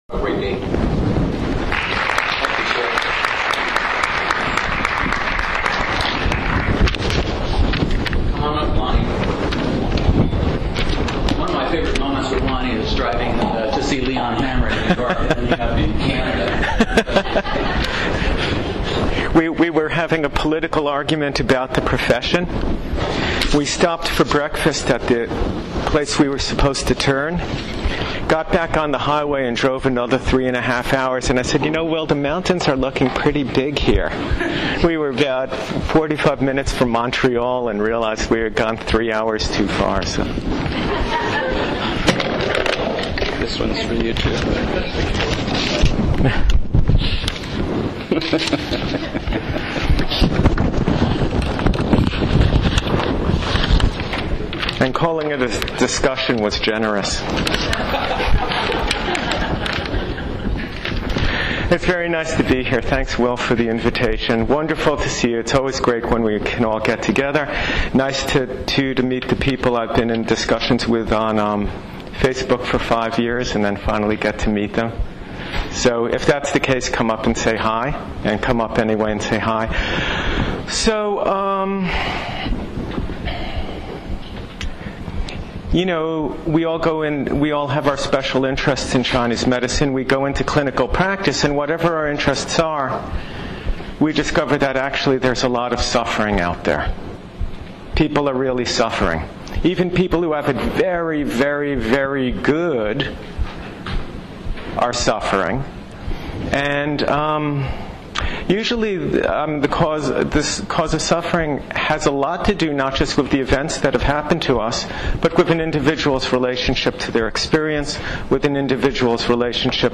Included here are my ten minute introduction to the general assembly as well as the first session(1:47).